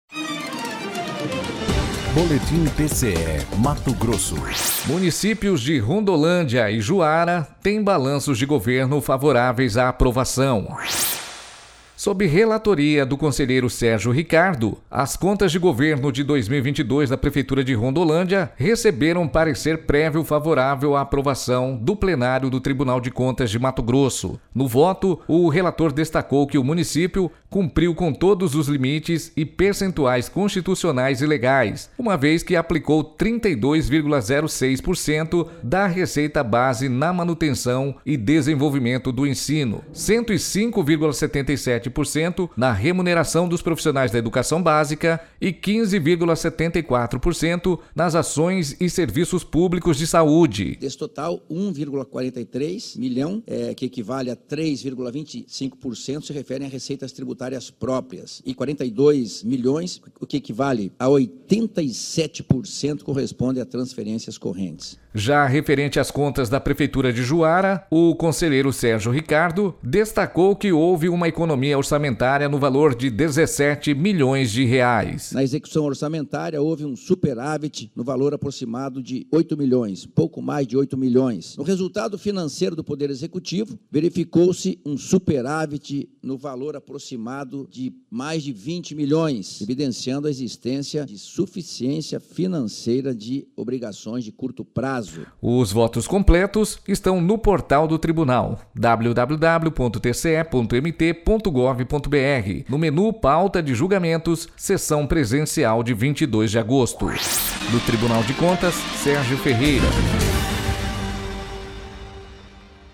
Sonora: Sérgio Ricardo – conselheiro do TCE-MT